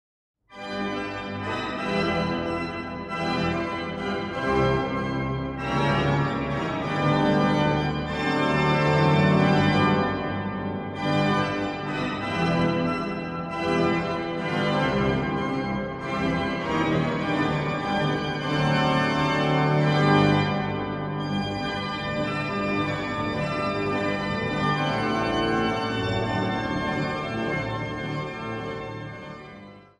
orgel